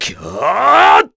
Bernheim-Vox_Casting2_kr.wav